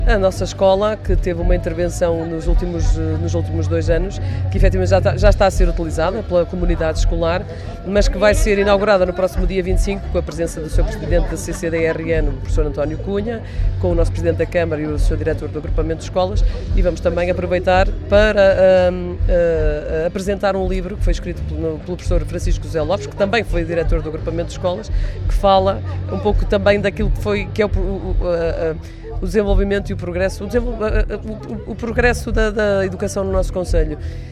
O programa de inauguração contará também a apresentação do livro “Das crianças sem escolas, às escolas sem crianças”, da autoria do historiador alfandeguense, Francisco José Lopes, um trabalho que apresenta a evolução do ensino no concelho, da segunda metade do século XVIII à atualidade, como acrescenta a vice-presidente: